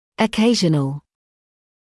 [ə’keɪʒənl][э’кейжэнл]периодический; повторный; случайный; редкий